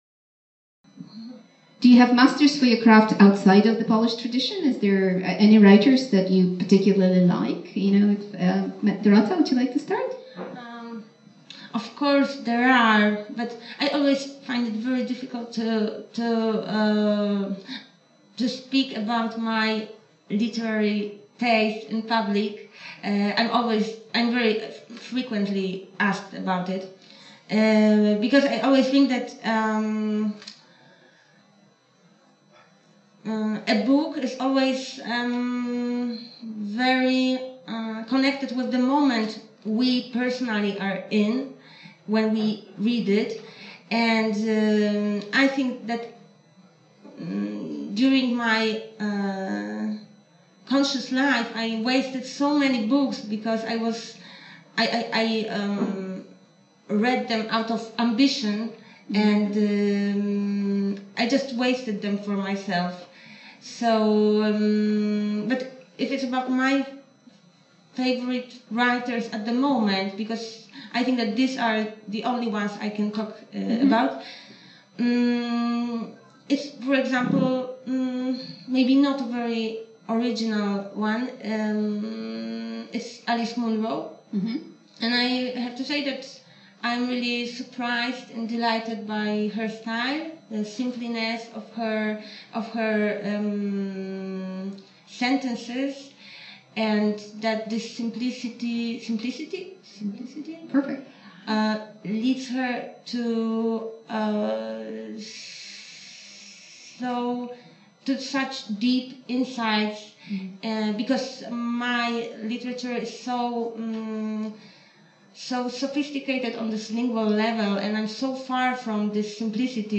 The authors’ literary inspirations (unedited)